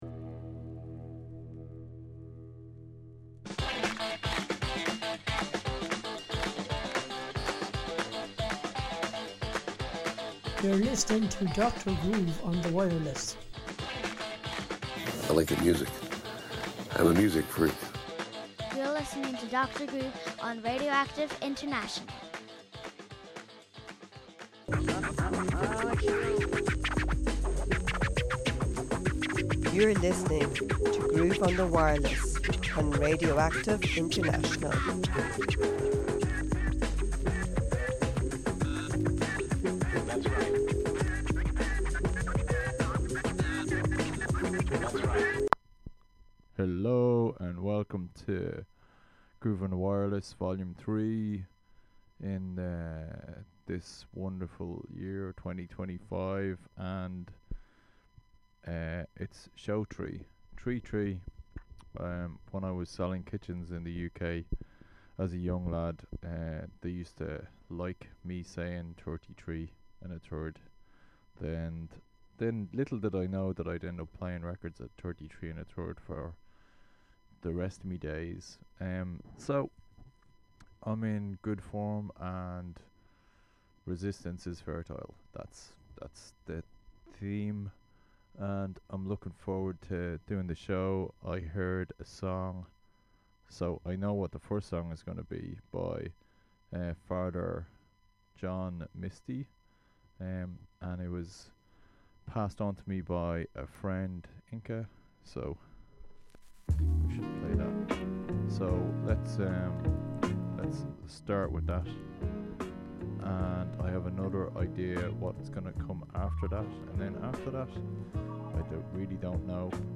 Alternative Online Free Radio
Music